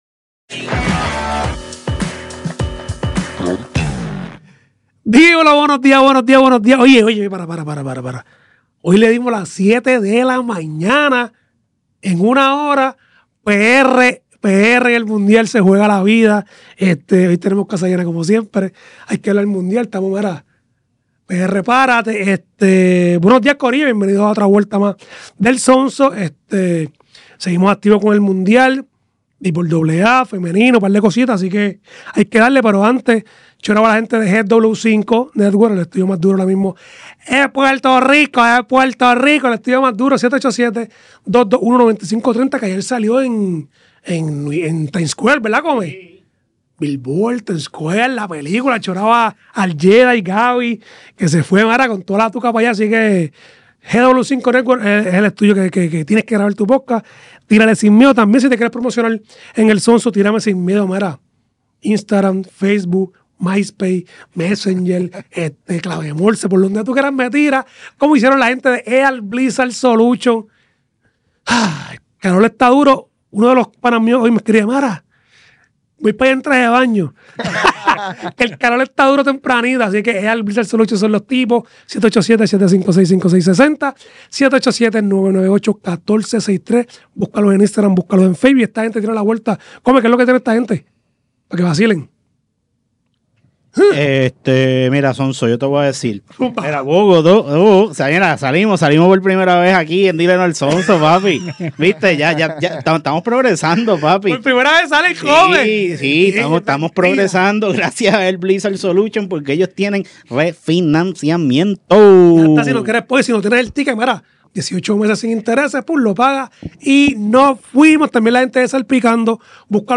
Grabado en GW5